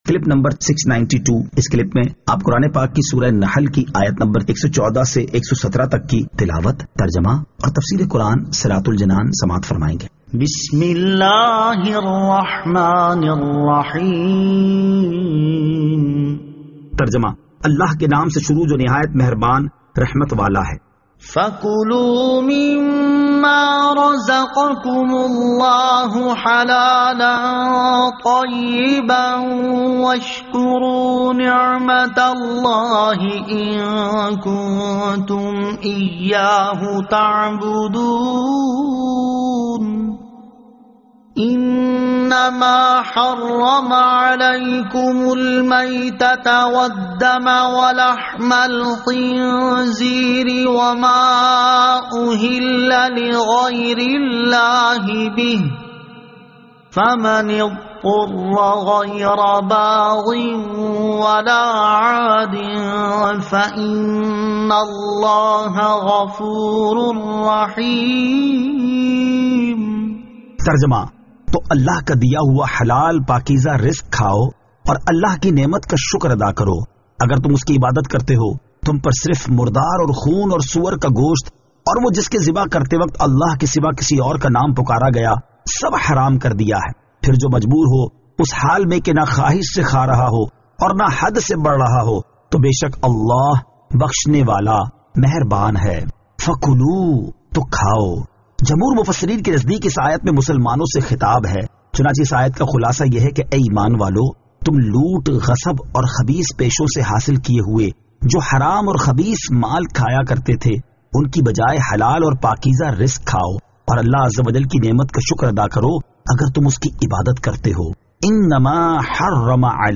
Surah An-Nahl Ayat 114 To 117 Tilawat , Tarjama , Tafseer